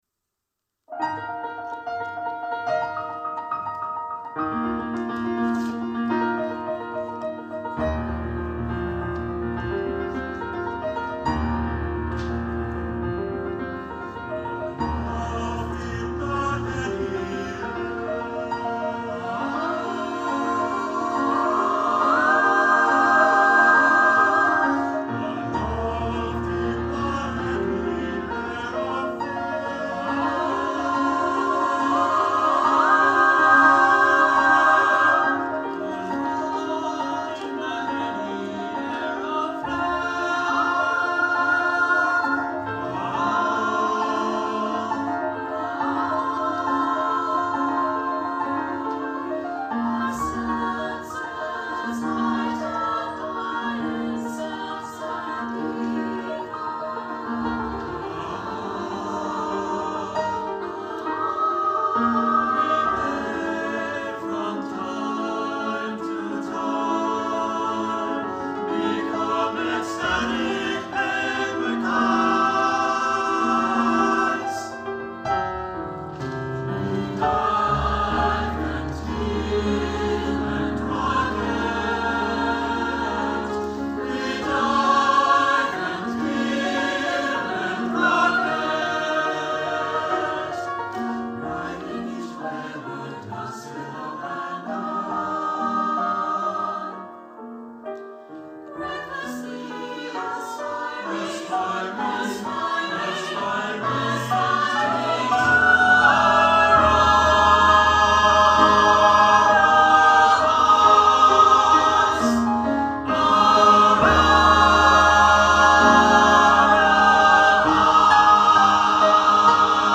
SATB, flute, horn, violin, cello, piano OR SATB, piano